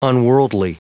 Prononciation du mot unworldly en anglais (fichier audio)
Prononciation du mot : unworldly